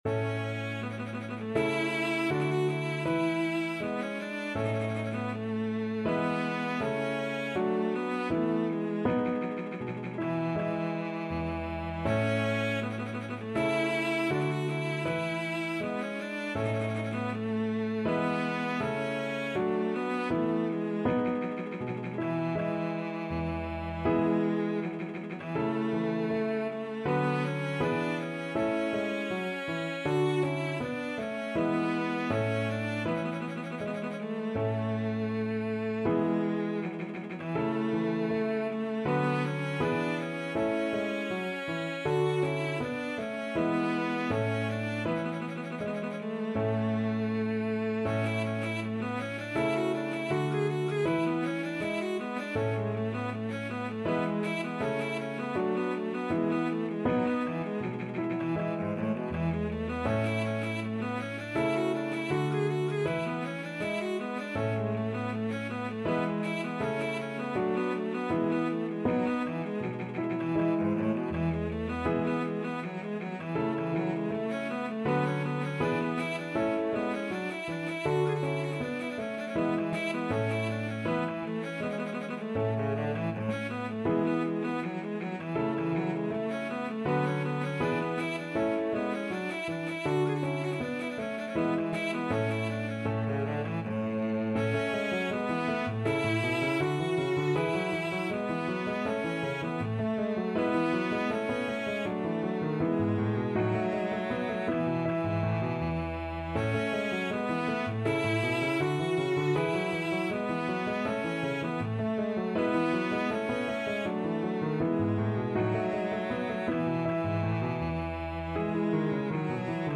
Classical Pachelbel, Johann Aria Cello version
Cello
4/4 (View more 4/4 Music)
Allegretto =80
A major (Sounding Pitch) (View more A major Music for Cello )
Classical (View more Classical Cello Music)